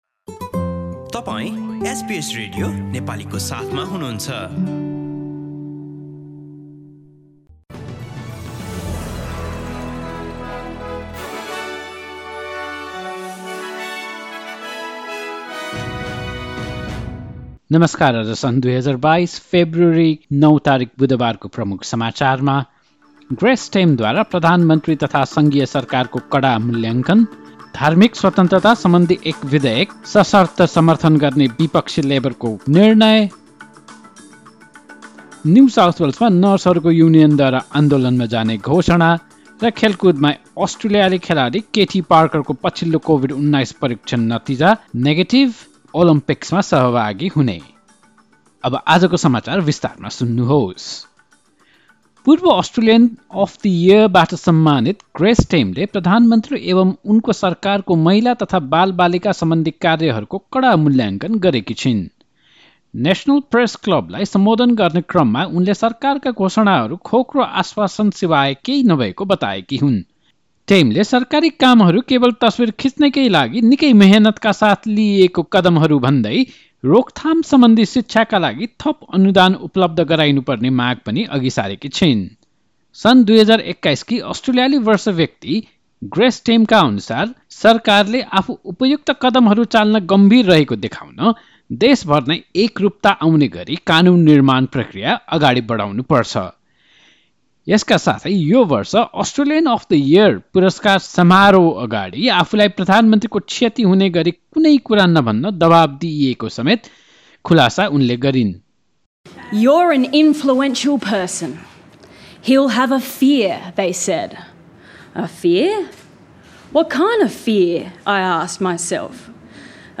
Listen to the latest news headlines from Australia in Nepali. In this bulletin, Grace Tame delivers a scathing assessment of the Prime Minister and Federal Government; Labor will support the Federal Government's contentious religious discrimination bill and the union representing New South Wales nurses and midwives says his members have voted to take industrial action.